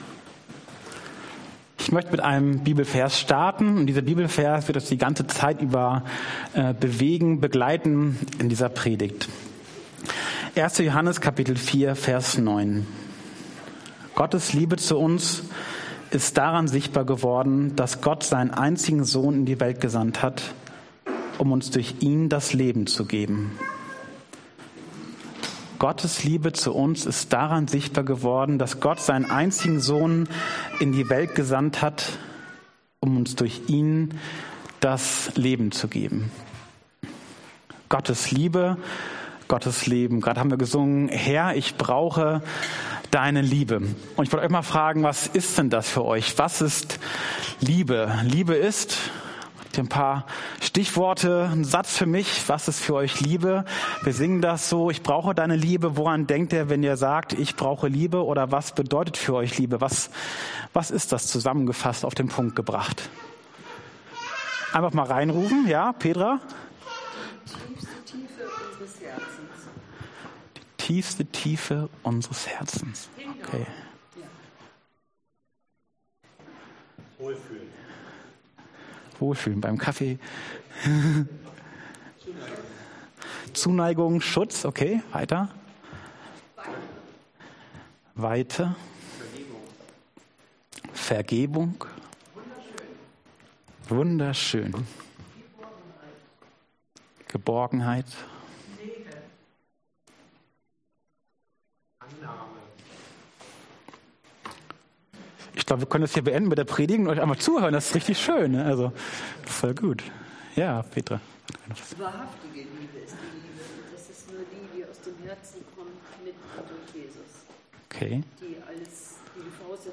Trinität Dienstart: Predigt Themen: Heilsgeschichte « Jesus hat keine Berührungsängste!